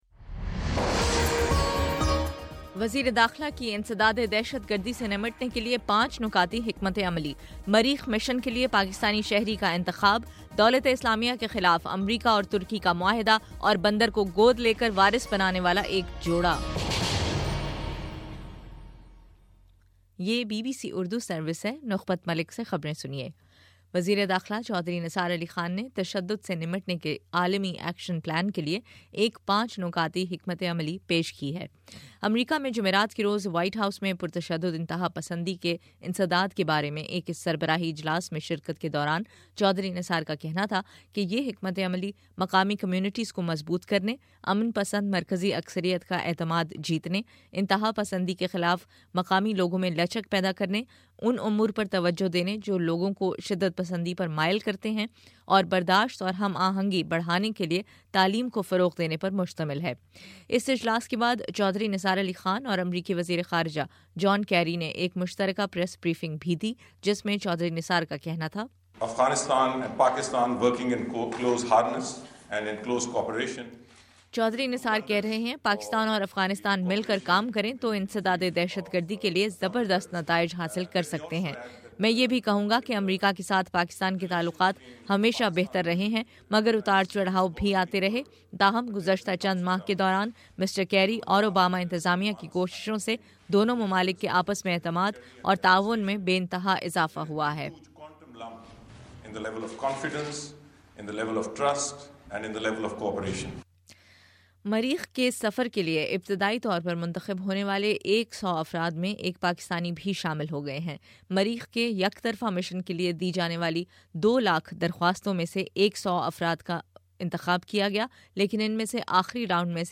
فروری 20 : صبح نو بجے کا نیوز بُلیٹن